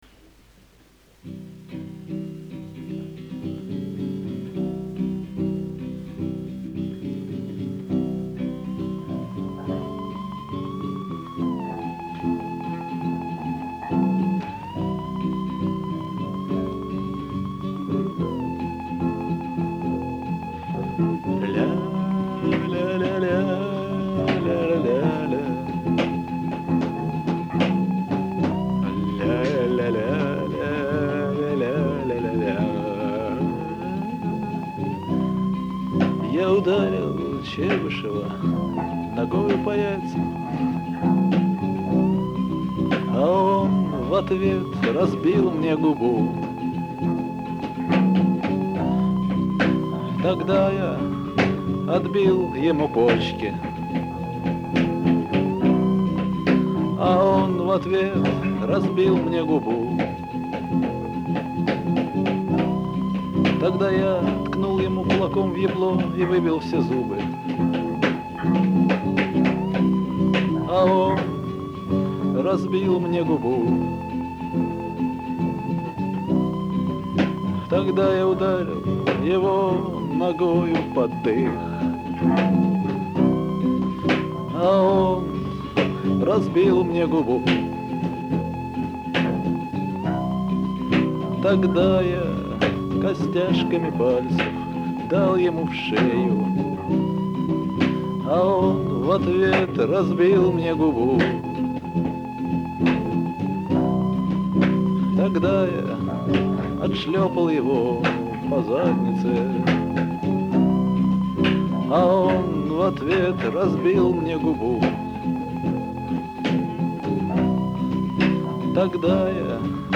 спонтанно записали в домашних условиях
бас, барабаны
вокал, гитара, тексты